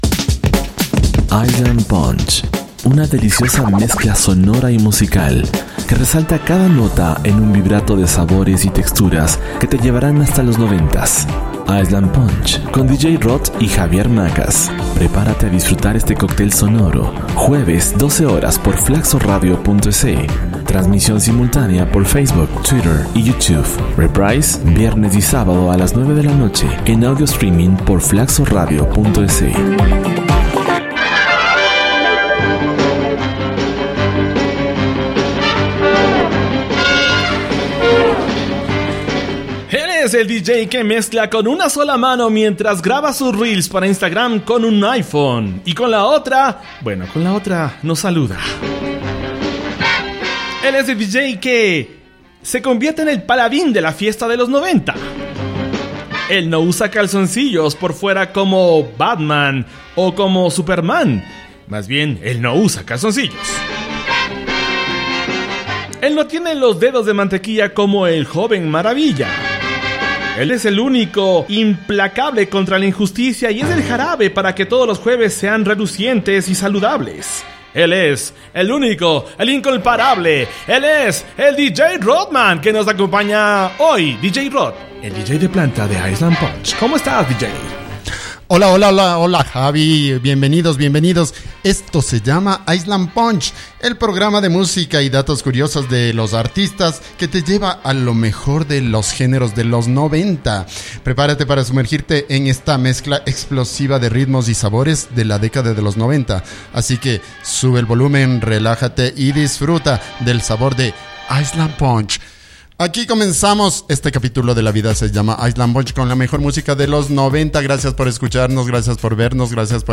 mucho moviento sonoro